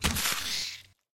Spider_die.ogg